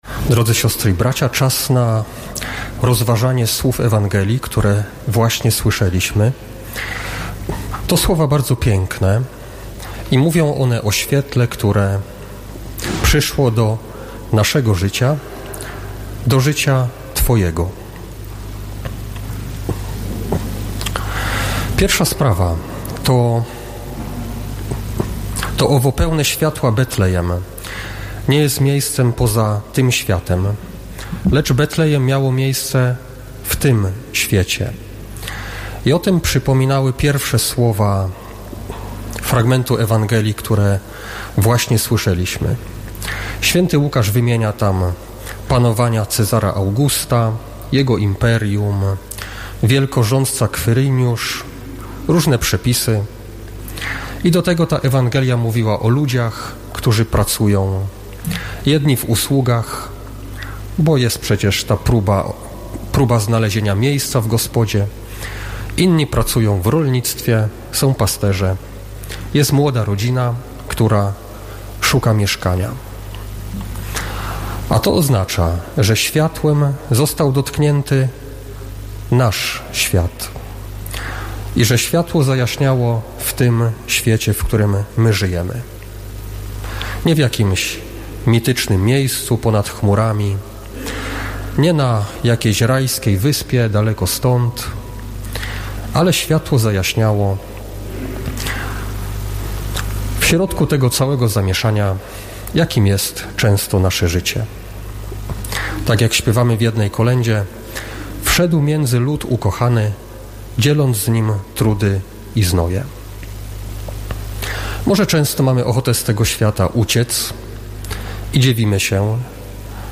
W noc Bożego Narodzenia w Katedrze Wrocławskiej ks. bp Maciej Małyga, biskup pomocniczy archidiecezji wrocławskiej, przewodniczył uroczystej Pasterce.
Homilia poświęcona była rozważaniu słów Ewangelii (Łk 2, 1-14)